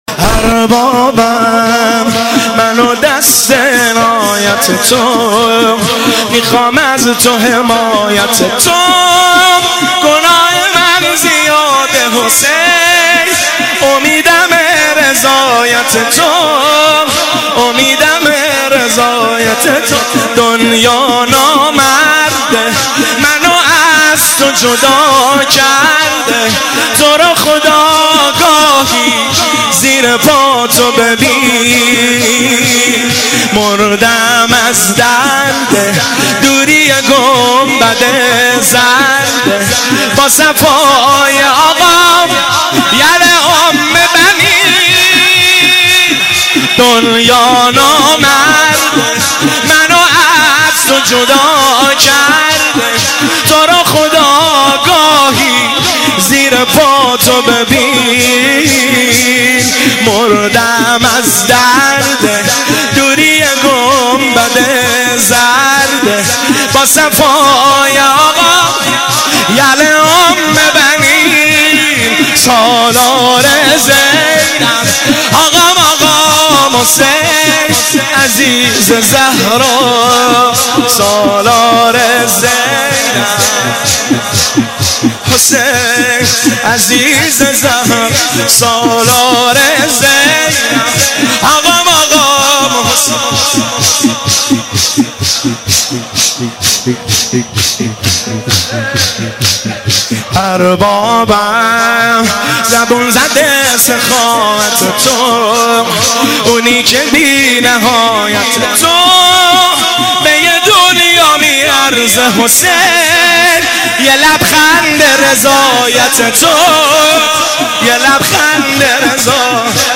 مداحی اربابم منو دست عنایت تو(شور)
شب دوم ایام مسلمیه 1396
هیئت بین الحرمین